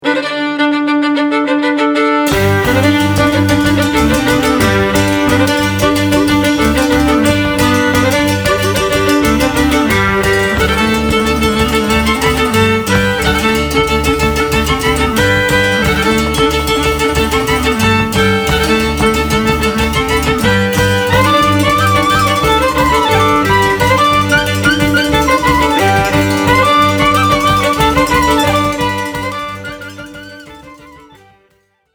Violin
C Clarinet
Accordions, Tsimbl
Bass Cello
Genre: Klezmer.